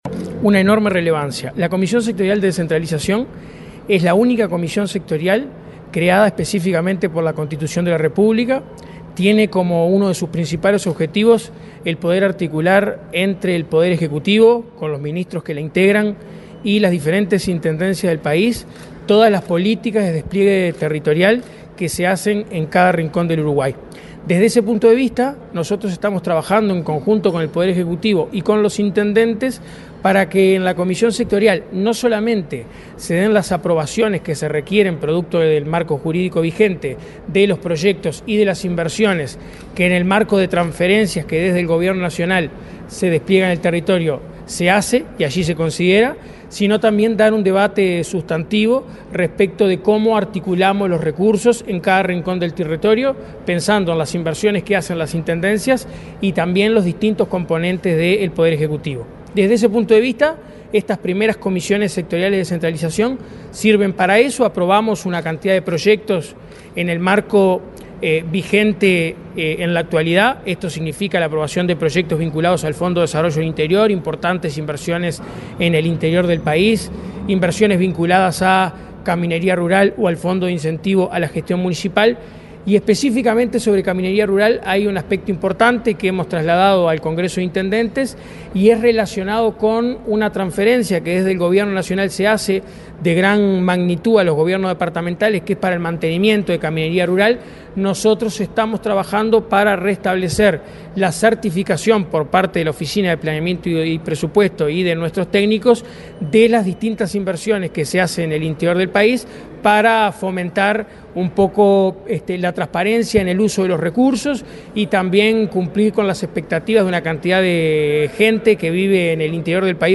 Entrevista al coordinador de Descentralización de OPP, Juan Manuel Arenas
Entrevista al coordinador de Descentralización de OPP, Juan Manuel Arenas 28/04/2025 Compartir Facebook X Copiar enlace WhatsApp LinkedIn El coordinador de Descentralización de la Oficina de Planeamiento y Presupuesto (OPP), Juan Manuel Arenas, dialogó con Comunicación Presidencial, este lunes 28 en la Torre Ejecutiva, luego de participar de la segunda reunión de la Comisión Sectorial de Descentralización, convocada por este Gobierno.